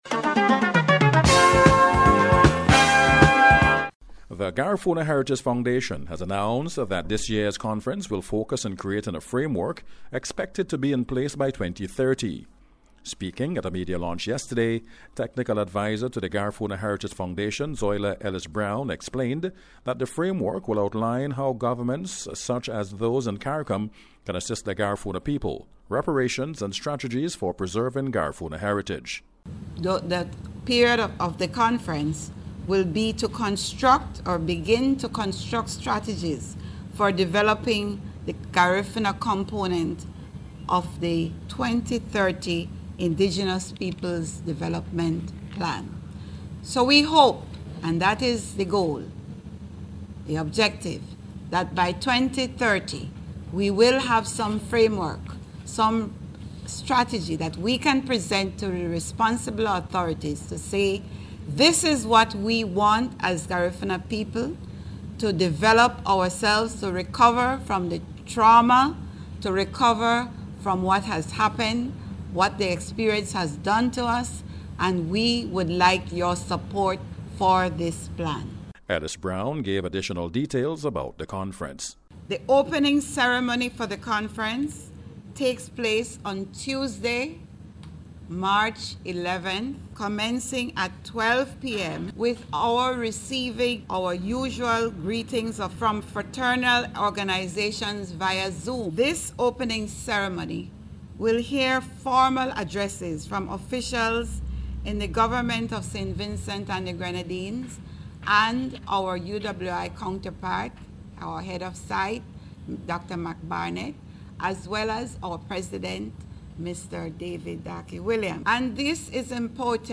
NBC’s Special Report- Wednesday 12th February,2025
GARIFUNA-FOUNDATION-REPORT.mp3